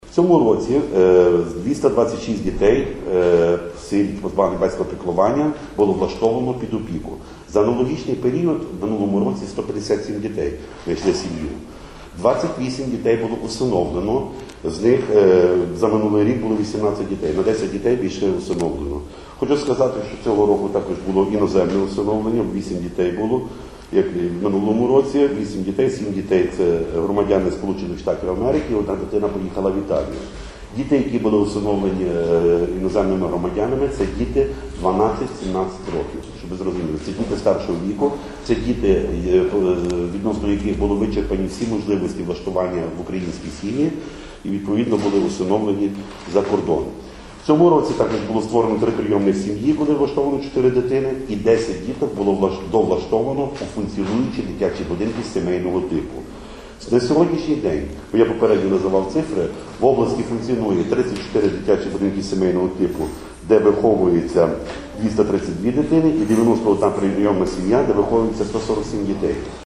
Про такі результати сьогодні, 31 липня, під час брифінгу розповів начальник Служби у справах дітей Львівської ОДА Володимир Лис.